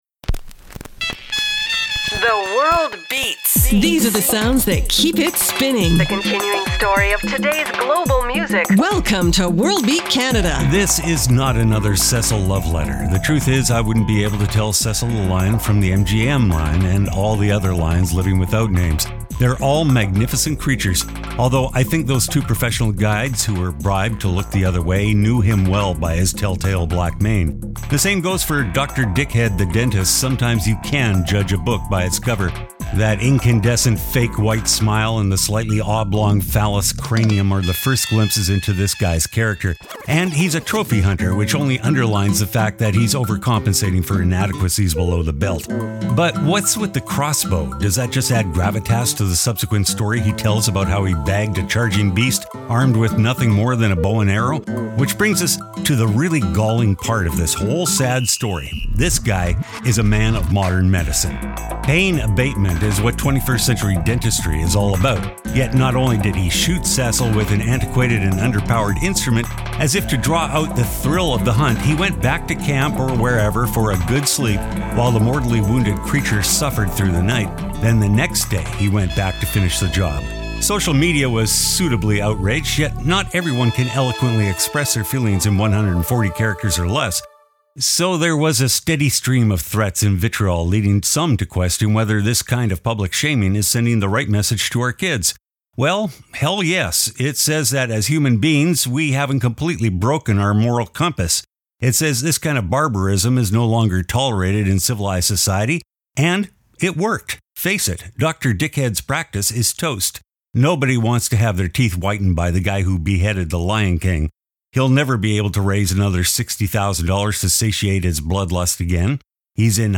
exciting contemporary global music alternative to jukebox radio
Cool new tropical vibes for the summer sun